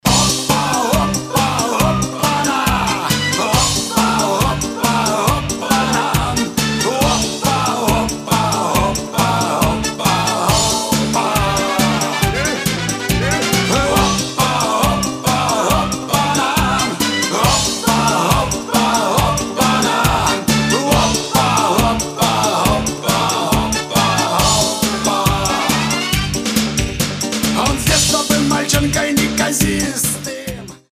Шансон рингтоны , Веселые